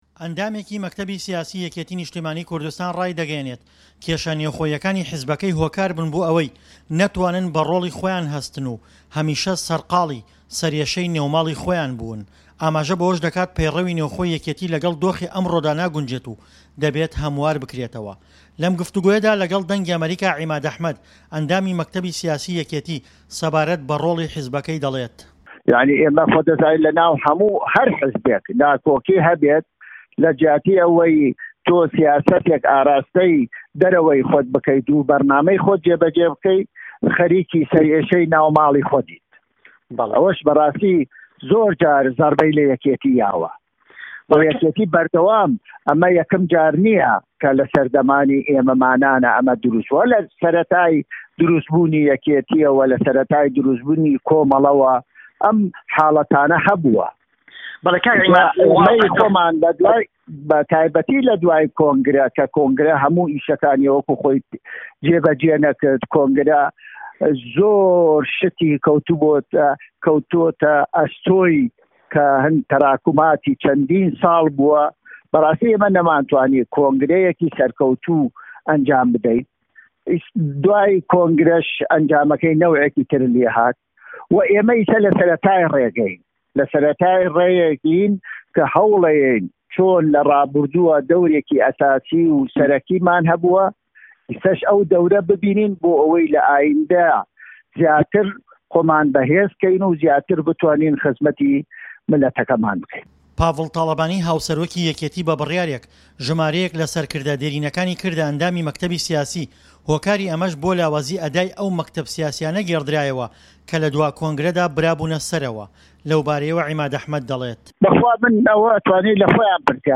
له‌م گفتووگۆیه‌دا له‌گه‌ڵ ده‌نگی ئه‌مه‌ریكا، عیماد ئه‌حمه‌د كه‌ له‌ چه‌ند ڕۆژی ڕابردوودا به‌بڕیاری بافڵ تاڵه‌بانی كرایه‌ ئه‌ندامی مه‌كته‌بی سیاسی حیزبه‌كه‌ی، ده‌ڵێت"یه‌كێتی هه‌میشه‌ خاوه‌ن ڕۆڵبووه‌، به‌ڵام ڕۆڵمان جاروبار هه‌ڵكشان و داكشانی به‌خۆیه‌وه‌ بینیووه‌، یه‌كێتی ئێستا خۆی به‌یه‌كێك له‌ هێزه‌ سه‌ره‌كیه‌كانی سه‌ر گۆڕه‌پانی هه‌رێم ده‌زانێت، هه‌رچه‌نده‌ كێشه‌ نێوخۆییه‌كانمان هه‌ندێك حاڵه‌تی دروستكرد، نه‌توانین به‌ڕۆڵی خۆمان هه‌ستین."